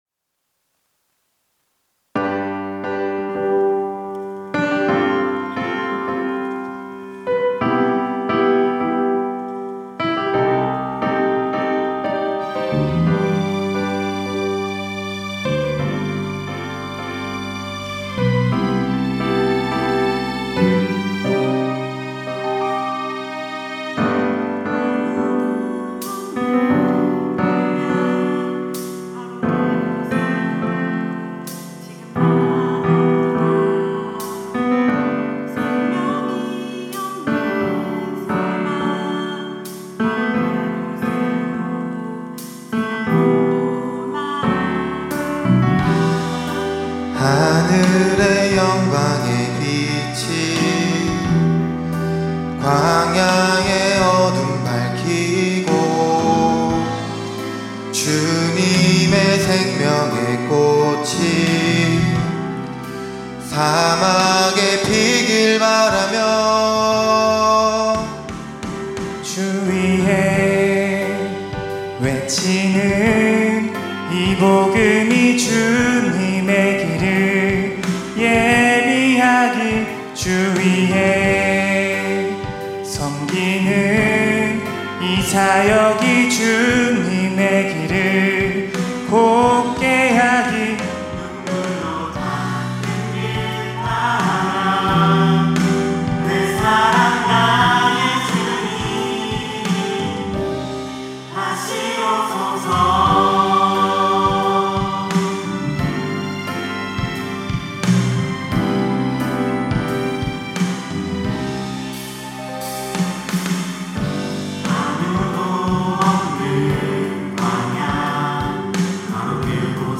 특송과 특주 - 하나님의 때